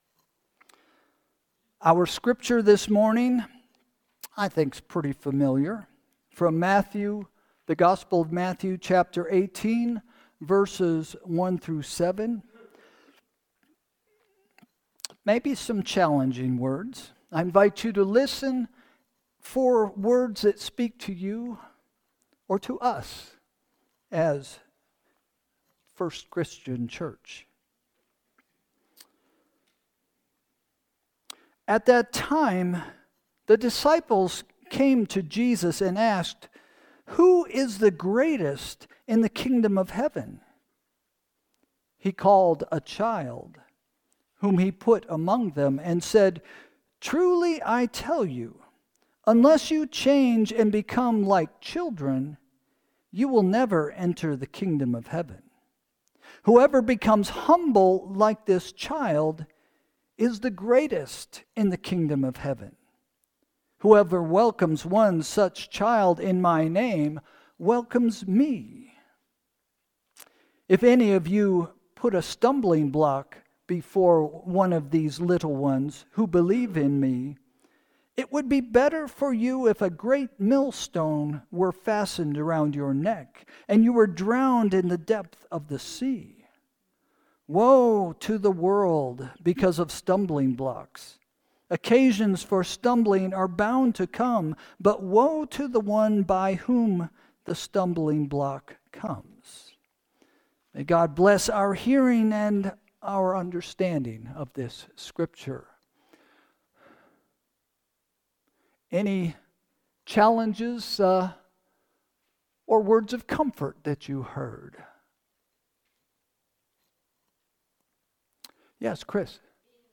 Sermon – March 23, 2025 – “Growing Small” – First Christian Church